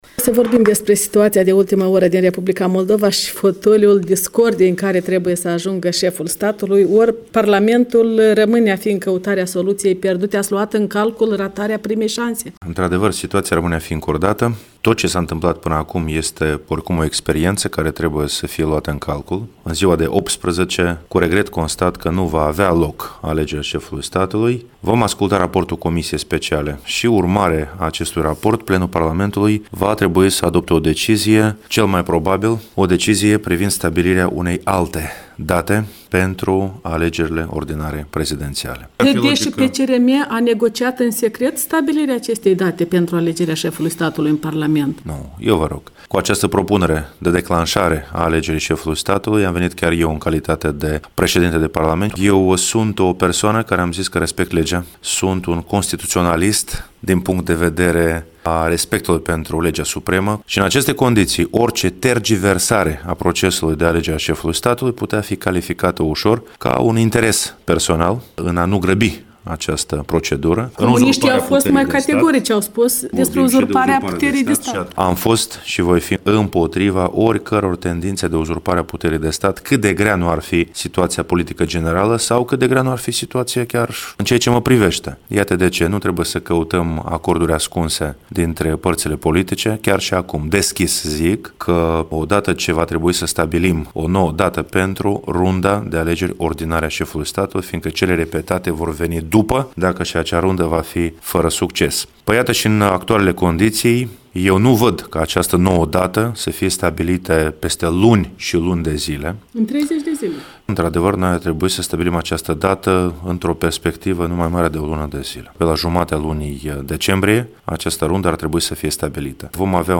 Un interviu cu Marian Lupu